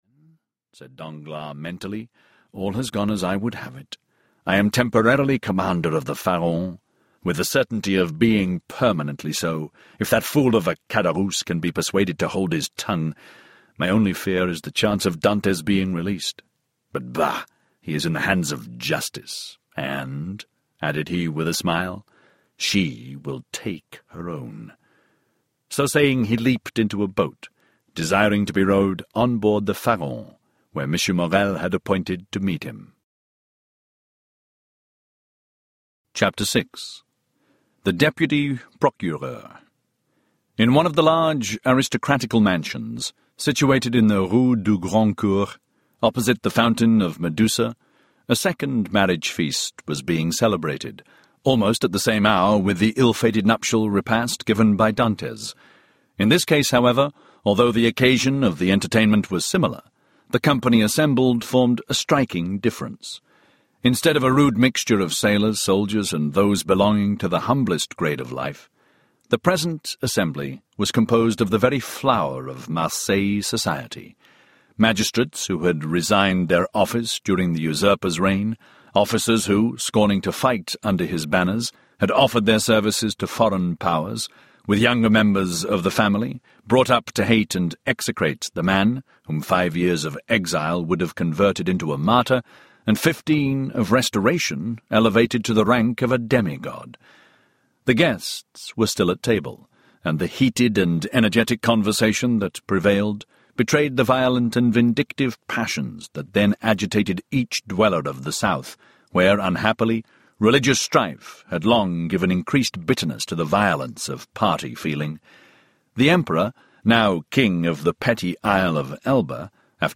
The Count of Monte Cristo Audiobook